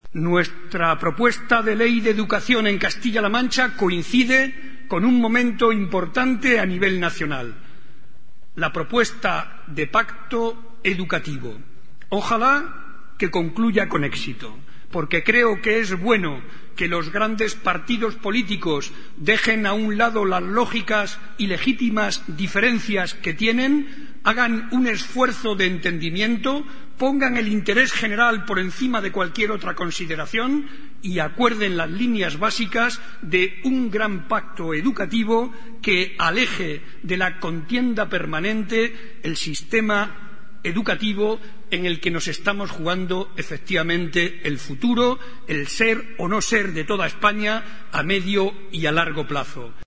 JCCM Viernes, 29 Enero 2010 - 1:00am El presidente de Castilla-La Mancha, José María Barreda, aseguró hoy en el Día de la Enseñanza en Guadalajara que la propuesta de ley de Educación en Castilla-La Mancha coincide con un momento importa nivel nacional como es la propuesta de Pacto Educativo.